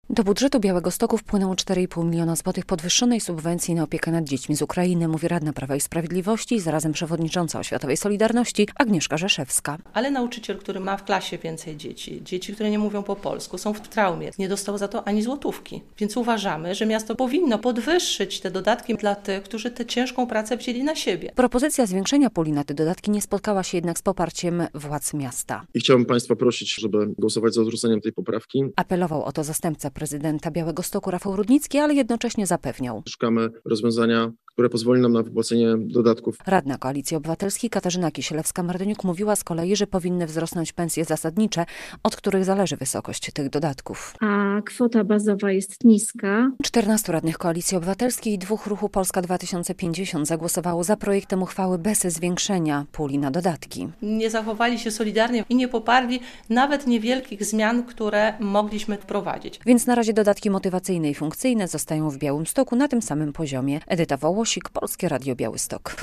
Białostoccy nauczyciele bez podwyżek wynagrodzeń - relacja